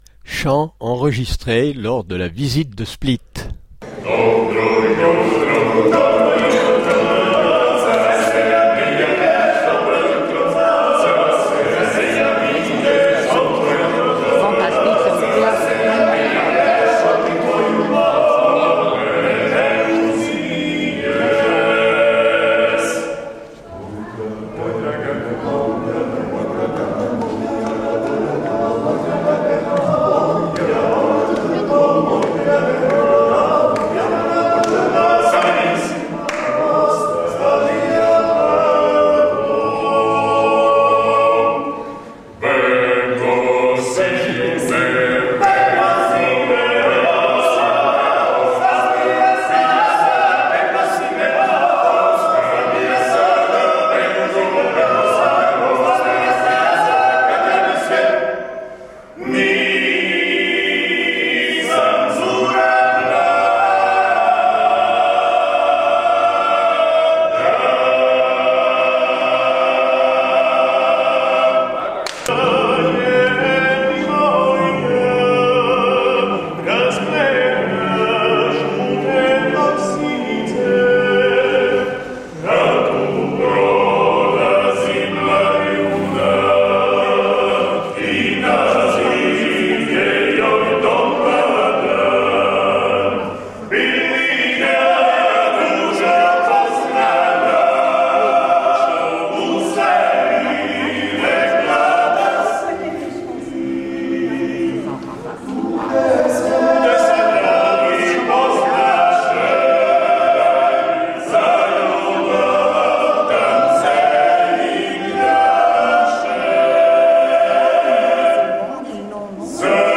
chants croates.mp3